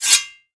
kn-hit-wall.wav